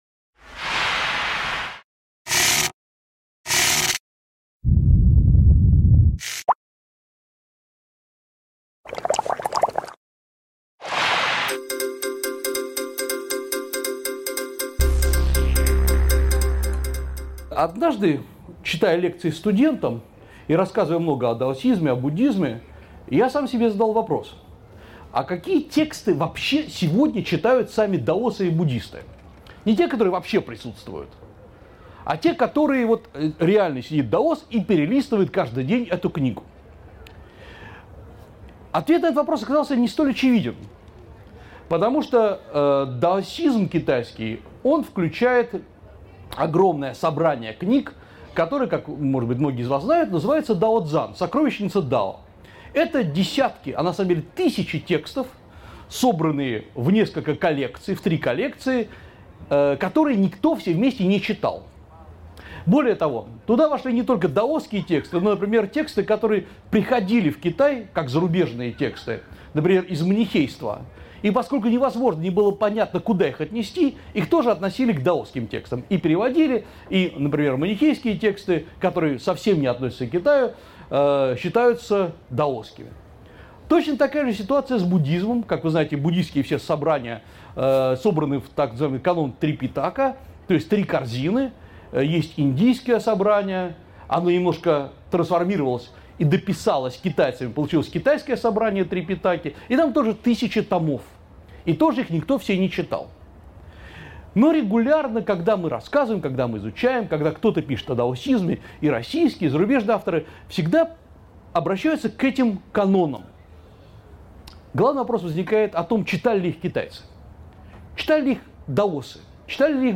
Аудиокнига Бессмертие, святость и плоть в китайской традиции | Библиотека аудиокниг